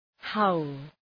Προφορά
{haʋl}